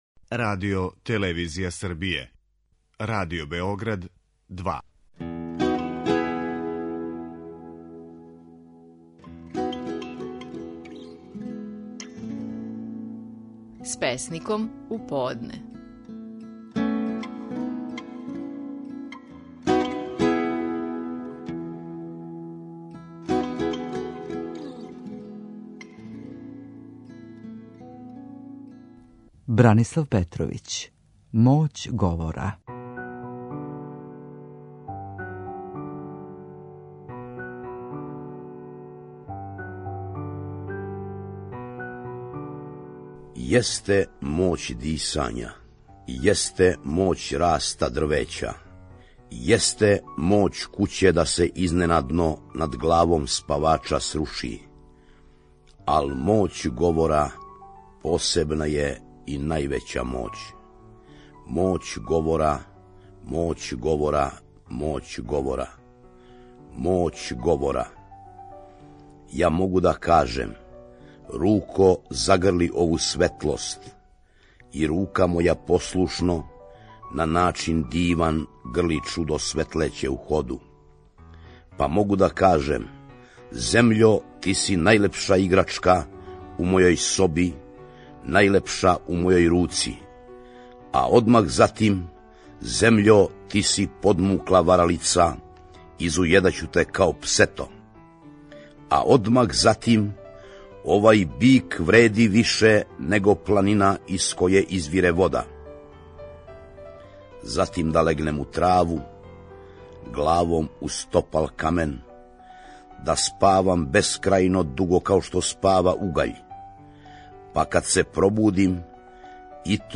Стихови наших најпознатијих песника, у интерпретацији аутора.
Бранислав Петровић говори своју песму: "Моћ говора".